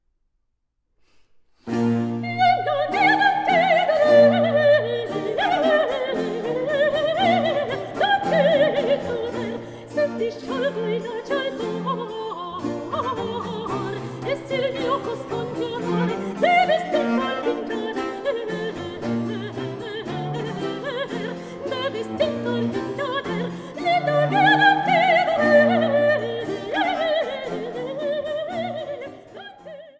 Sopran
Cembalo
Violoncello
Théorbe